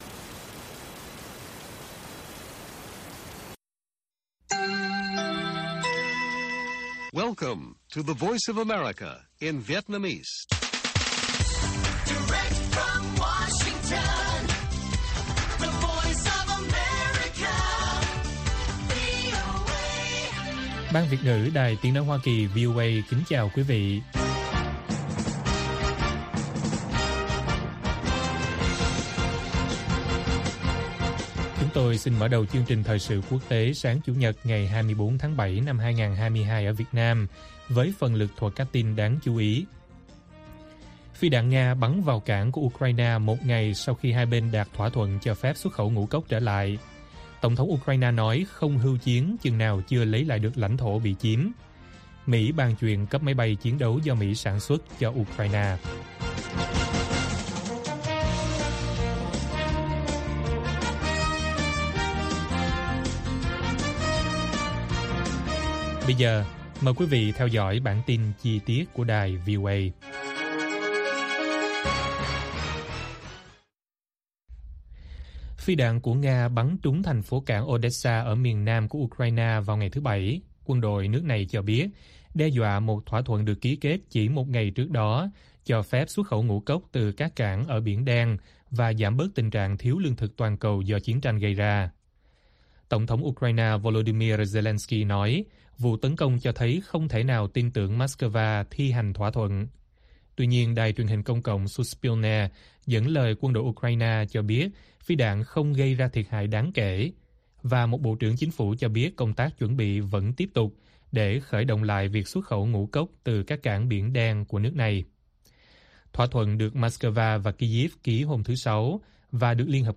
Phi đạn Nga bắn vào cảng Ukraine một ngày sau khi đạt thỏa thuận xuất khẩu ngũ cốc - Bản tin VOA